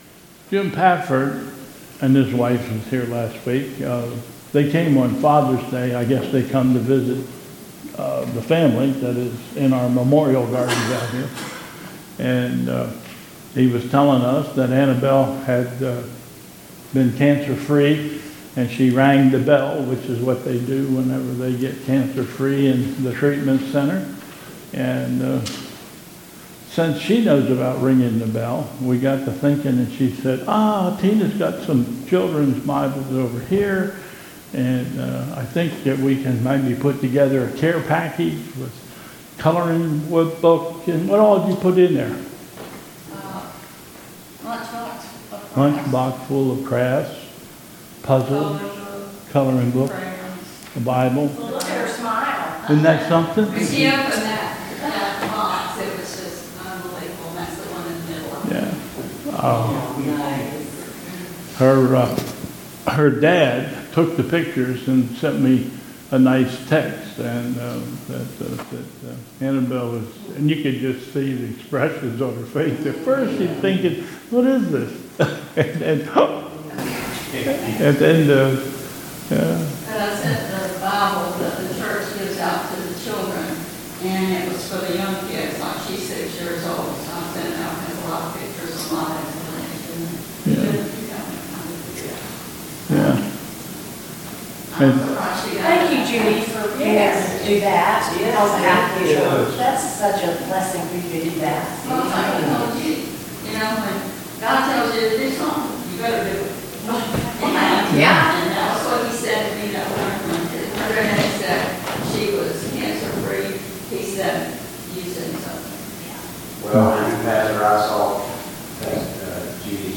2022 Bethel Covid Time Service